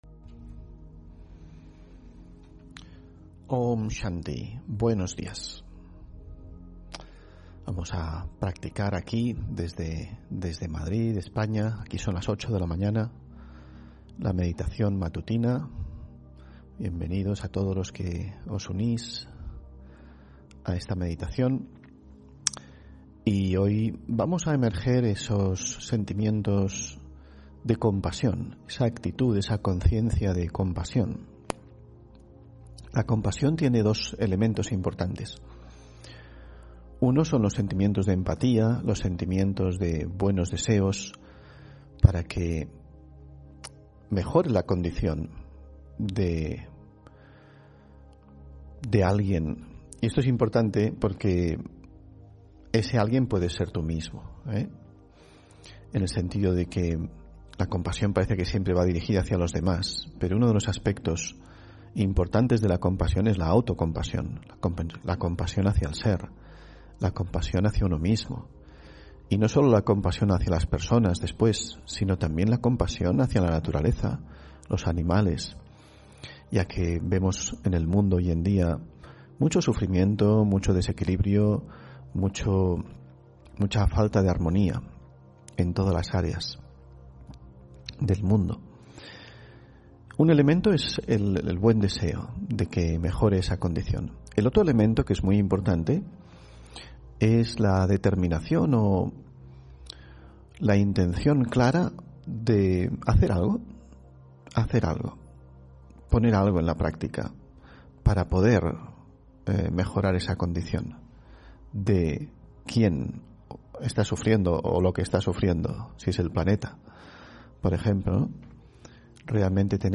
Meditación de la mañana Consciencia compasiva